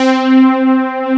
SYNTH C5.wav